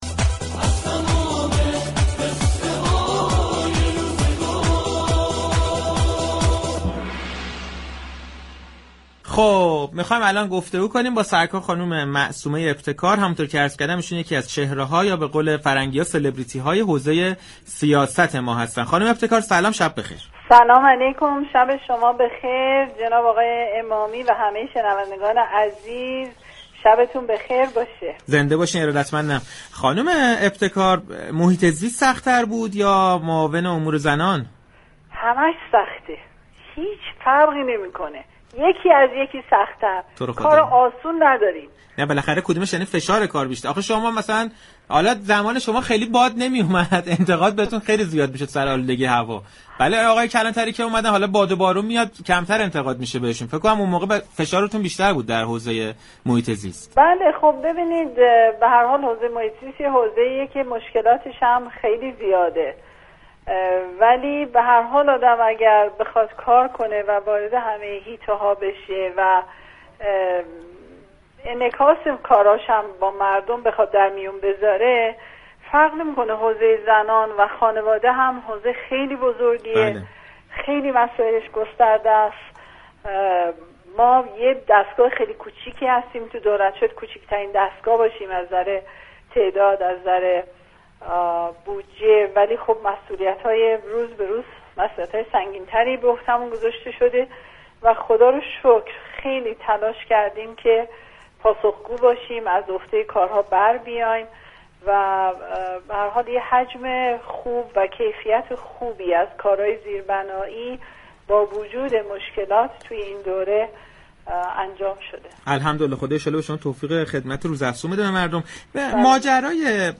معصومه‌ی ابتكار یكی از چهره‌های سیاسی كشورمان در گفتگوی تلفنی با برنامه‌ی صحنه‌ی رادیو تهران در رابطه با فعالیت‌های این زیرمجموعه‌ی نهاد ریاست جمهوری و اولویت‌های آن برای مخاطبان رادیو تهران مواردی را عنوان كرد.